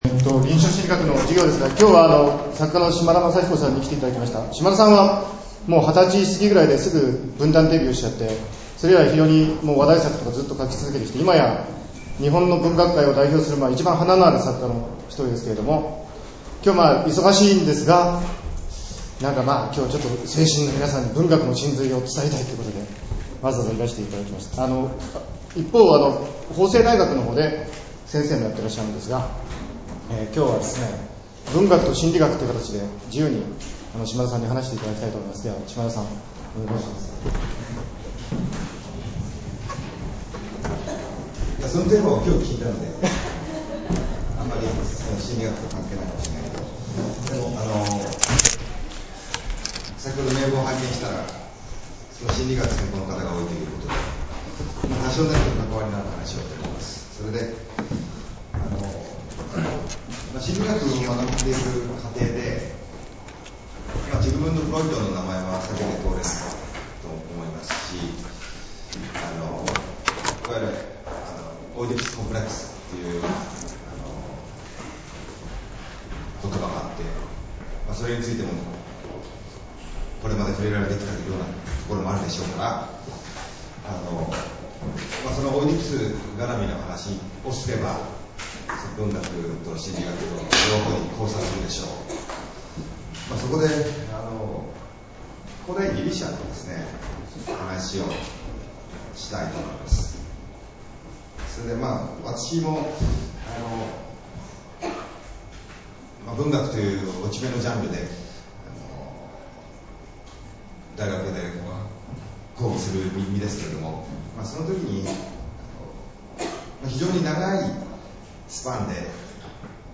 島田雅彦 聖心女子大学講義
「文学と神話」 Podcast登録 : RSS | Apple 聖心女子大学 MP3, 38.9MB, 85分 クオリア日記より抜粋： 午前中、聖心女子大学の臨床心理学特講には、 作家の島田雅彦さんにご出講いただいた。